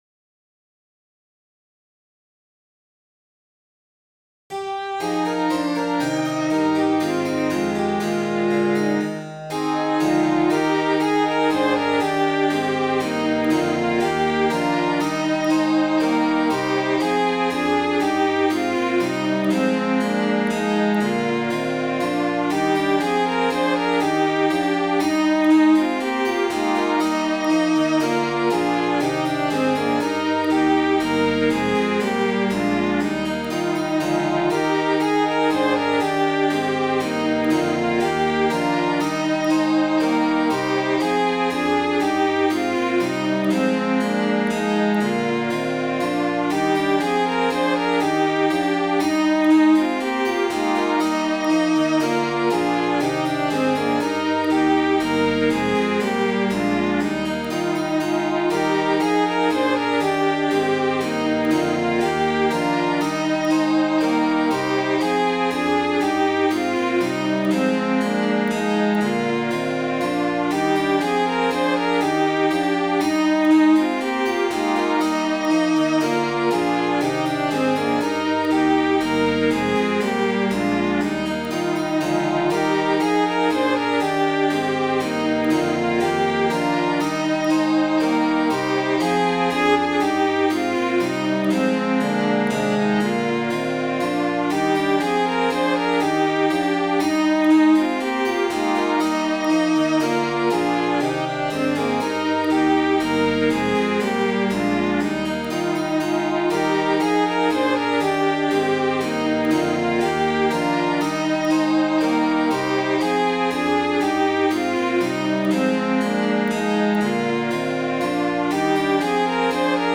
Midi File, Lyrics and Information to The Banks of the Sweet Primrosess